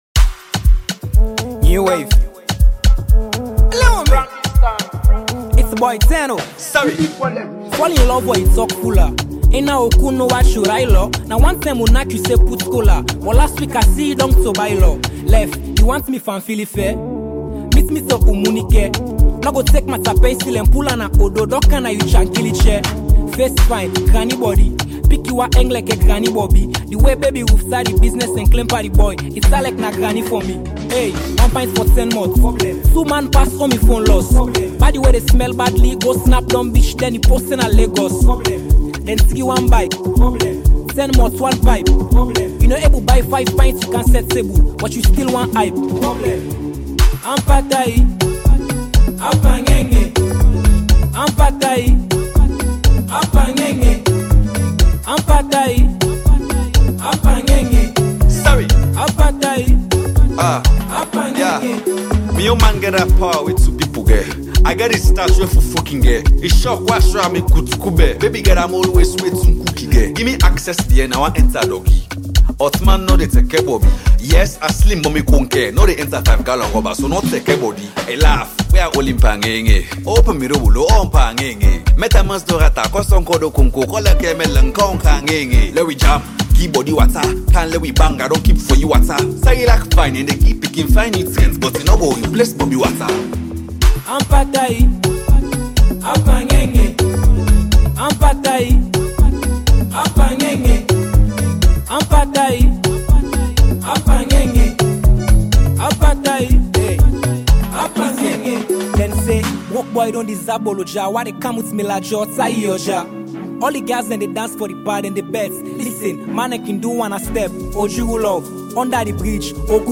Krio and Temne rapping skills